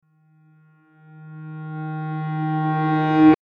ピアノ逆再生単発3.mp3